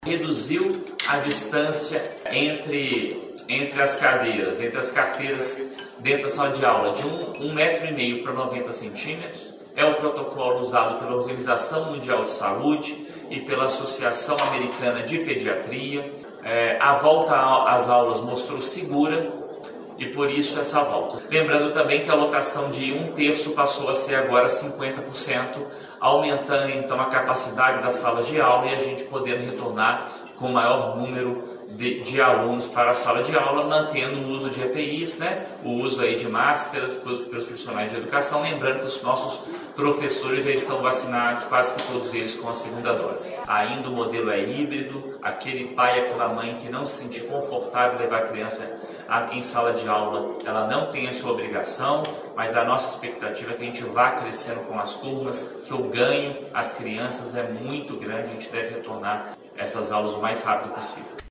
O secretário de Estado da Saúde, o médico Fábio Baccheretti, detalhou durante coletiva para a imprensa a atualização do protocolo que deve ser seguido pelas escolas mineiras para a manutenção do retorno seguro às atividades presenciais.